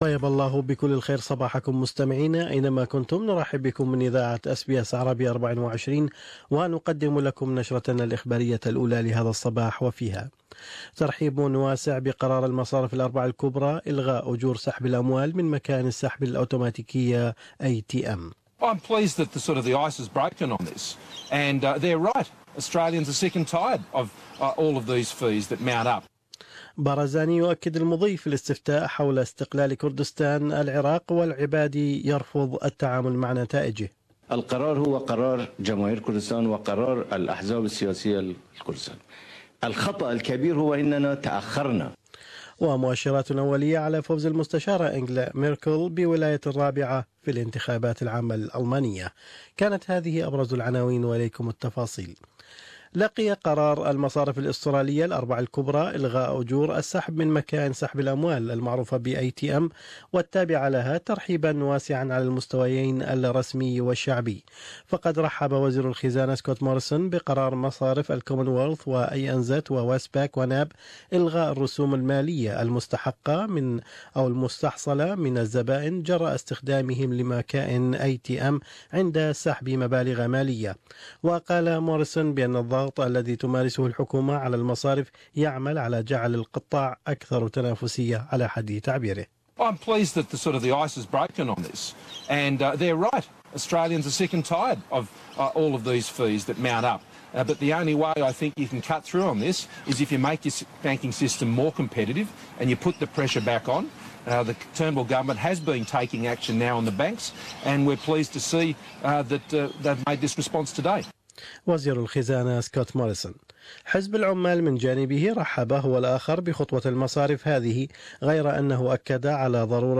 News Bulletin: Austalians welcome desicion by country's big banks to abolish ATM withdrawal fees